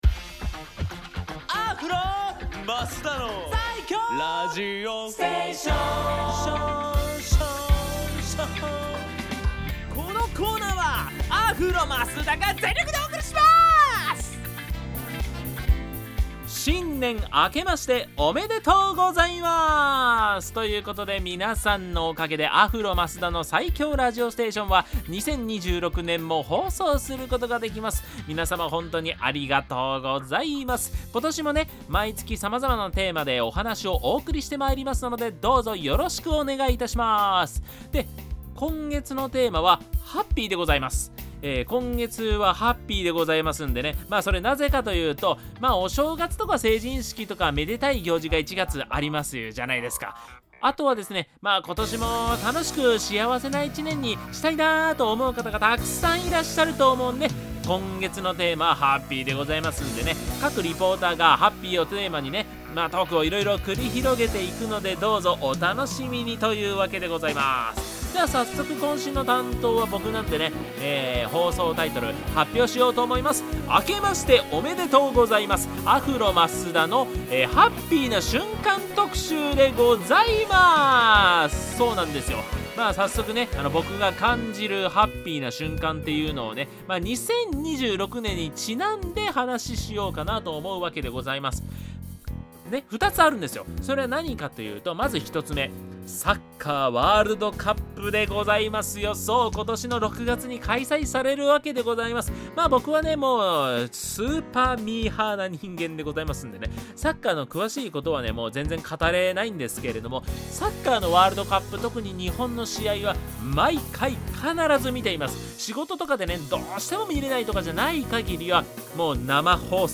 各リポーターがHappyをテーマにトークを繰り広げていきますので、どうぞよろしくお願い致します！
こちらが放送音源です♪